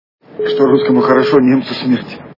При прослушивании Брат - Что русскому хорошо Немцу смерть качество понижено и присутствуют гудки.